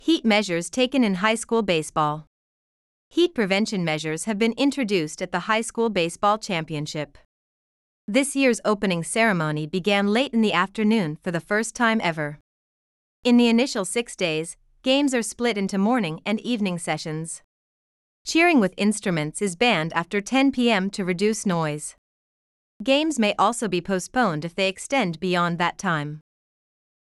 【ナチュラルスピード】